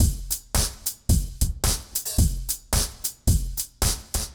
RemixedDrums_110BPM_18.wav